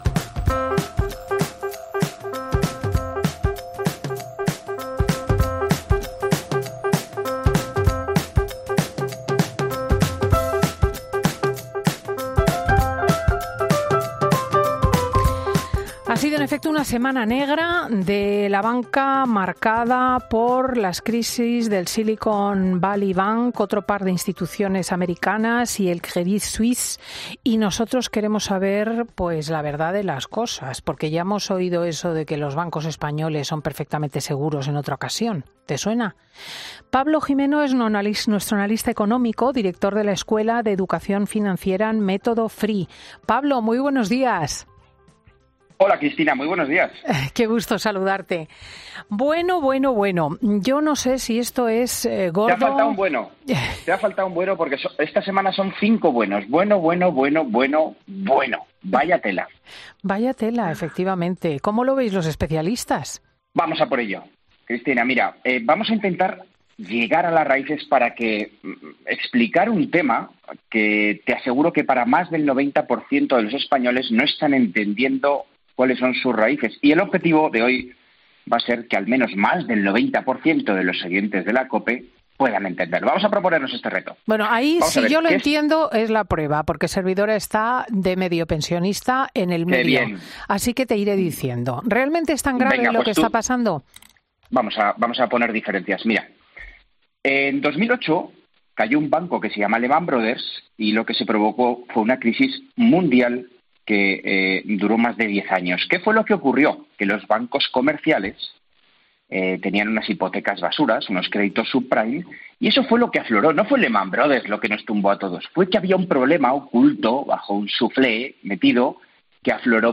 "Fin de Semana" es un programa presentado por Cristina López Schlichting, prestigiosa comunicadora de radio y articulista en prensa, es un magazine que se emite en COPE, los sábados y domingos, de 10.00 a 14.00 horas.